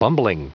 Prononciation du mot bumbling en anglais (fichier audio)
Prononciation du mot : bumbling